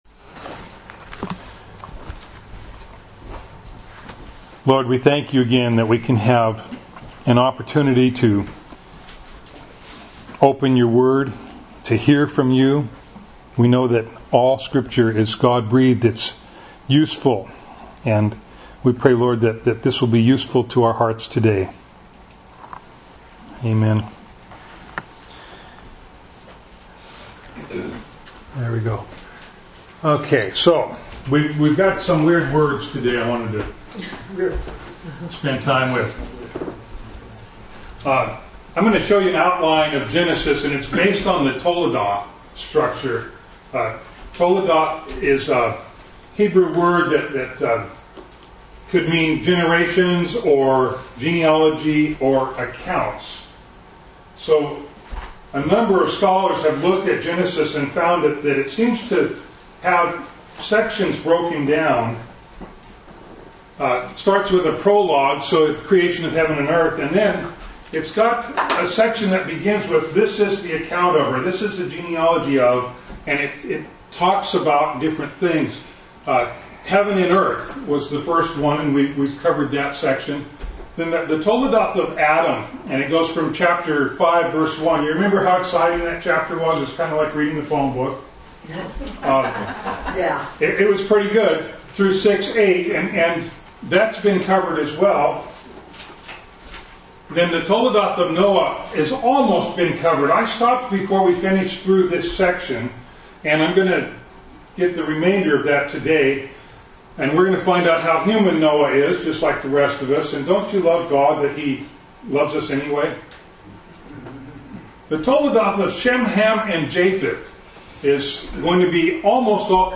The People Who Were Scattered – Skykomish Community Church